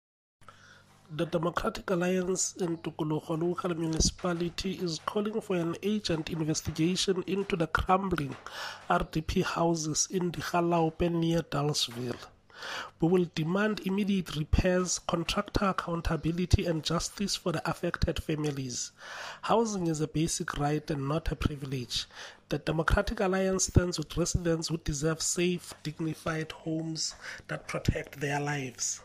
Sesotho soundbites by Cllr Hismajesty Maqhubu.